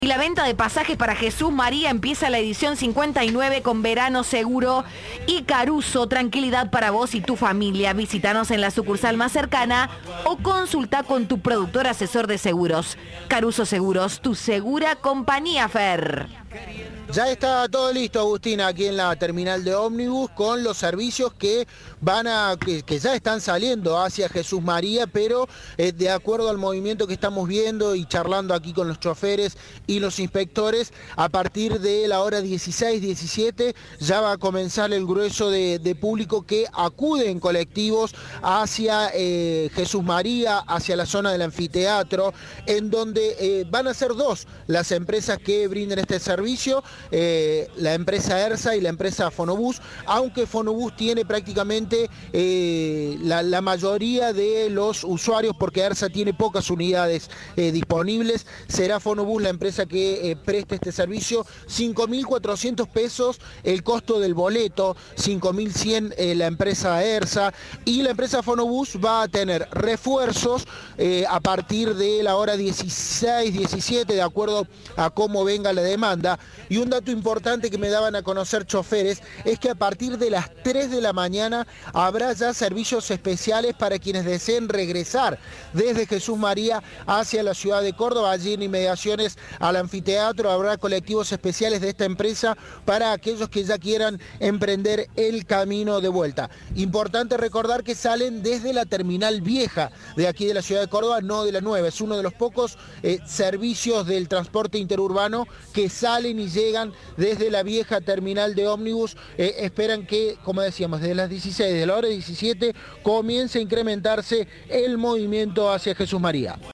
El emocionante relato de la coronación del Albirrojo